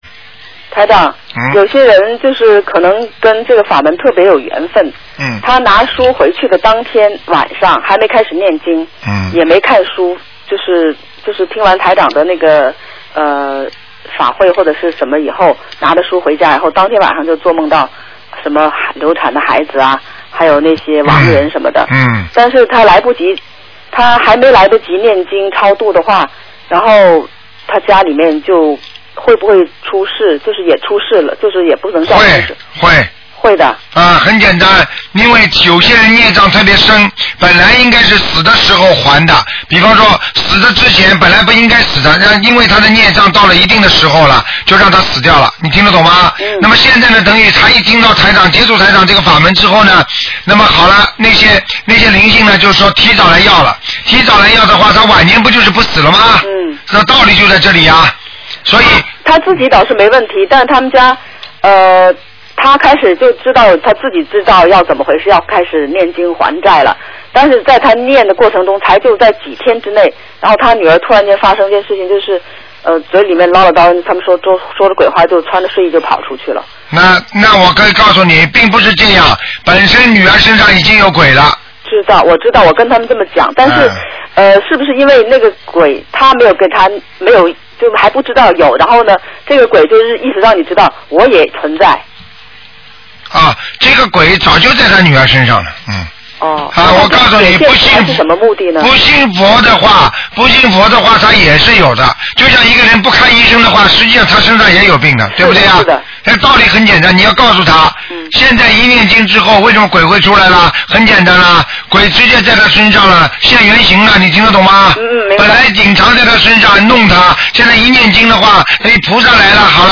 目录：剪辑电台节目录音_集锦